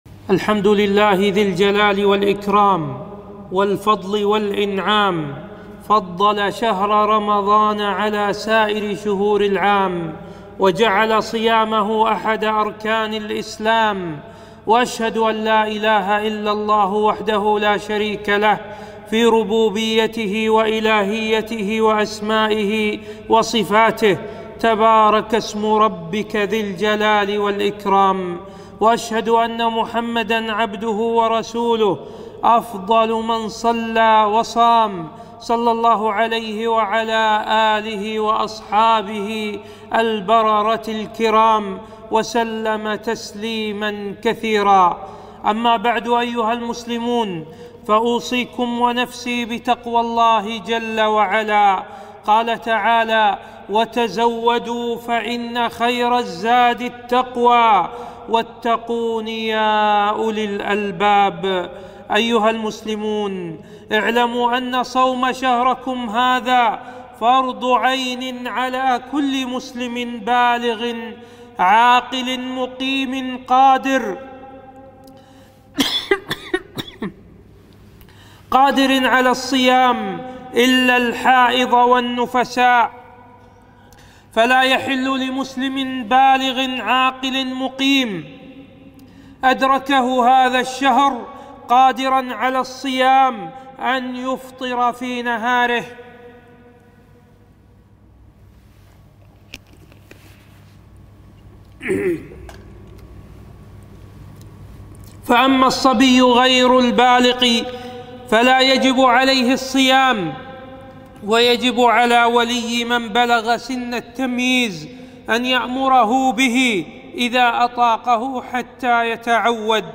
خطبة - ملخص أحكام الصيام